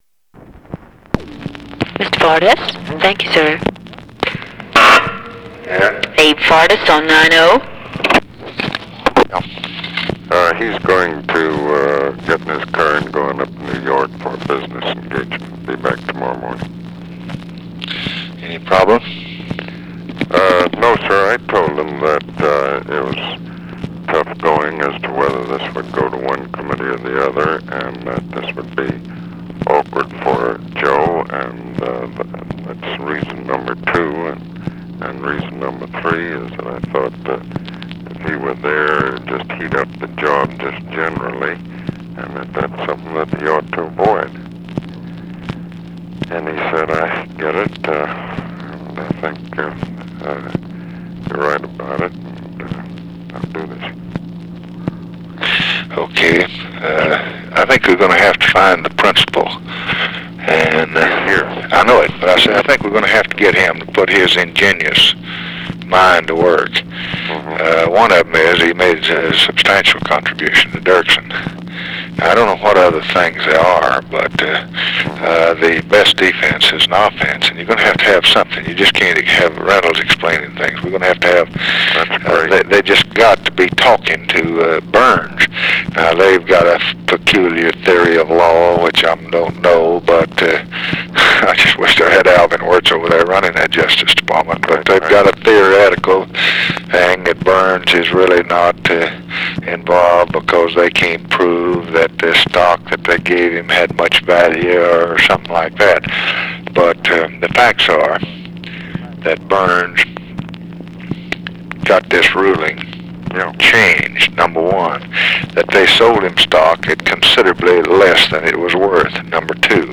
Conversation with ABE FORTAS, September 10, 1964
Secret White House Tapes